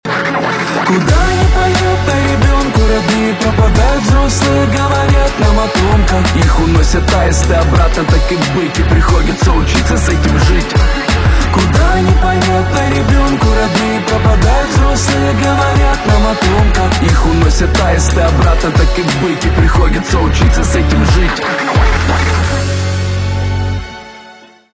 русский рэп
депрессивные
басы